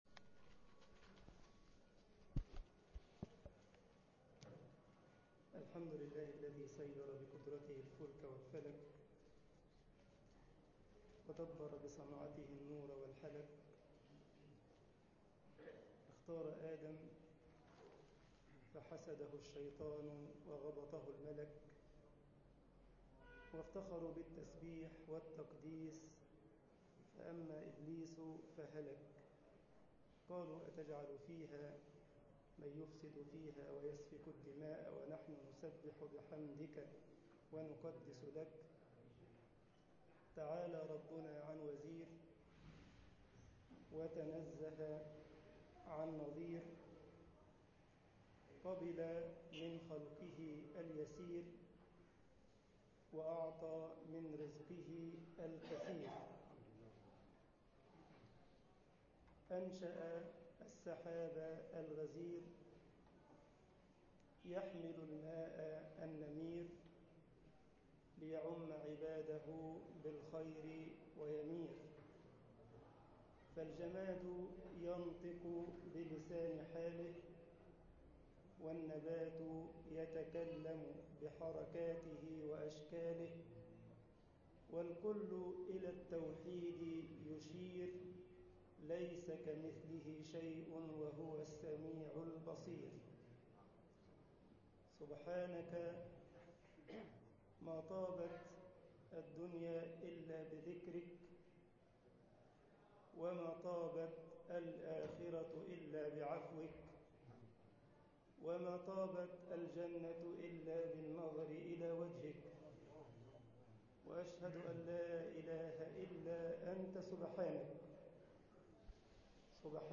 مسجد المنوفي غمرة ـ الشرابية محاضرة